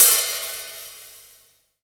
drum-hitfinish.wav